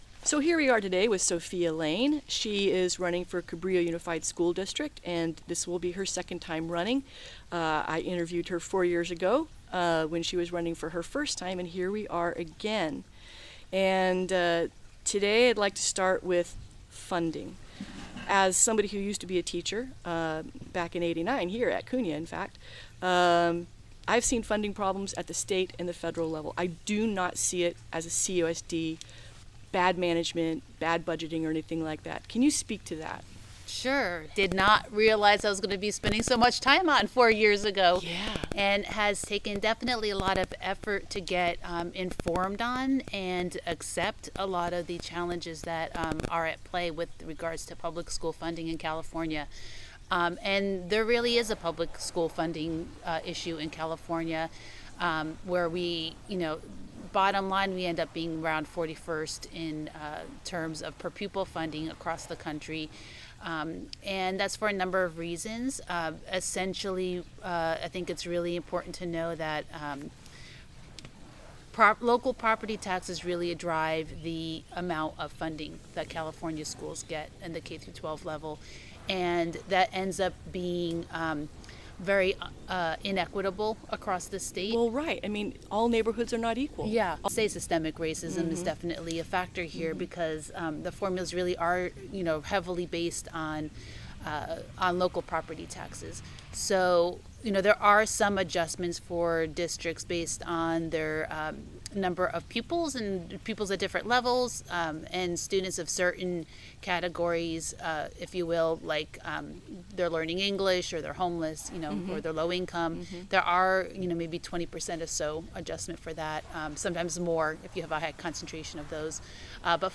Apologies for some of the background noises. It is much more difficult to control background sound in a suburban backyard. Yes, that is a wood chipper towards the end!